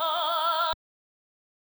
Tm8_Chant64.wav